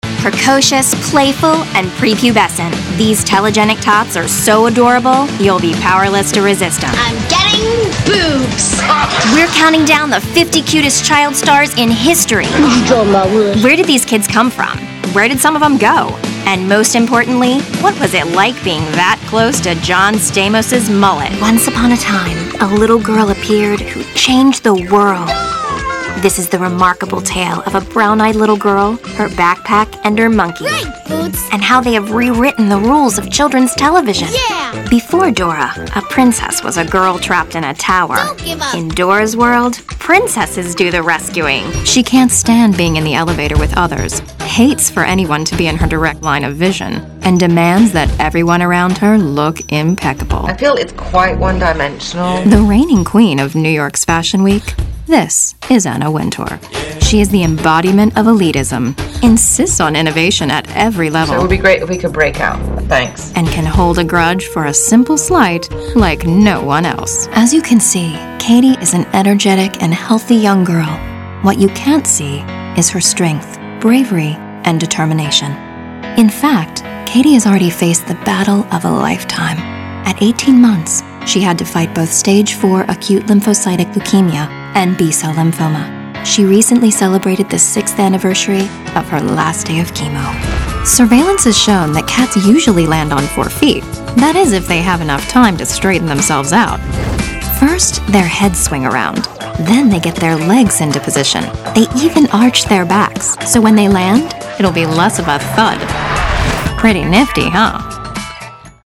Versatile, bright, young, corporate, professional, sultry, intelligent, relatable, storyteller
Sprechprobe: Sonstiges (Muttersprache):